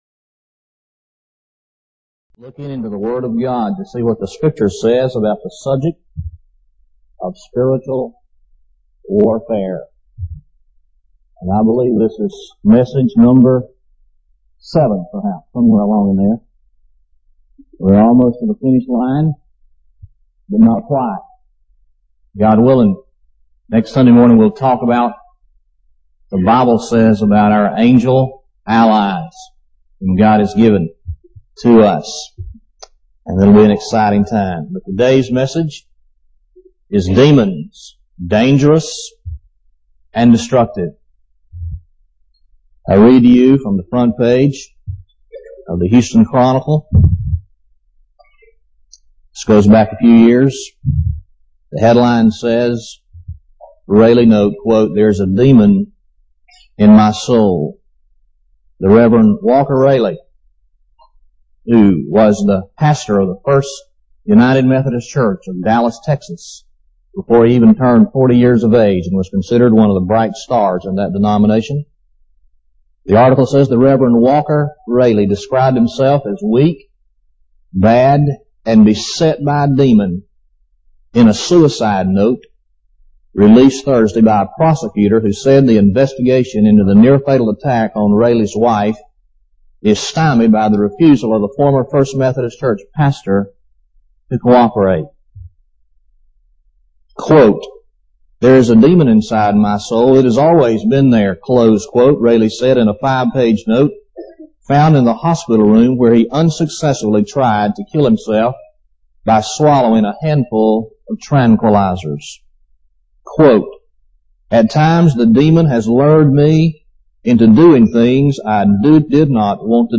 Lakeview Baptist Church - Auburn, Alabama